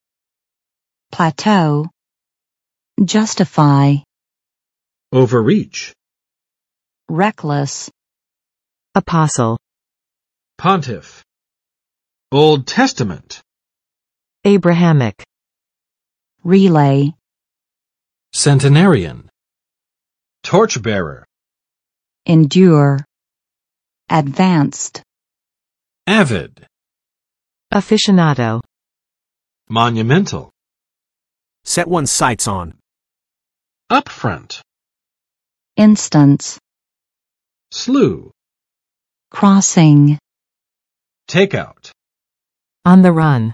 [plæˋto] v. 进入停滞期; 达到平稳状态